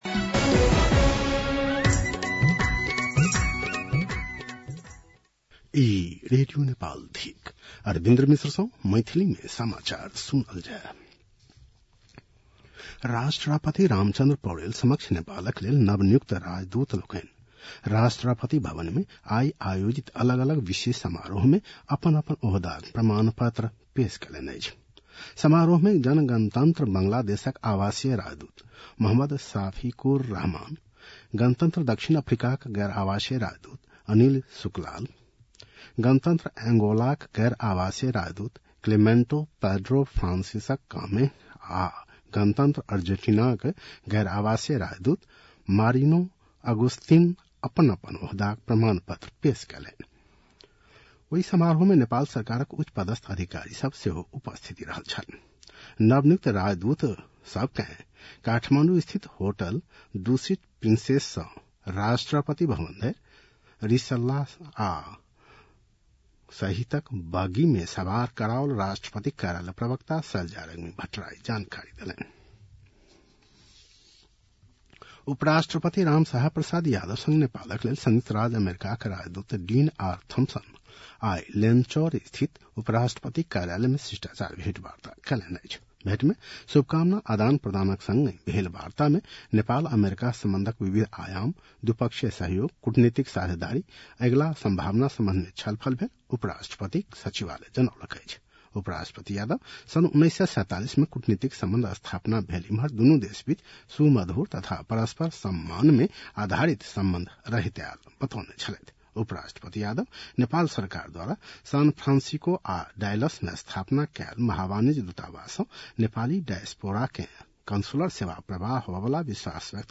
मैथिली भाषामा समाचार : ९ असार , २०८२
Maithali-news-3-09-.mp3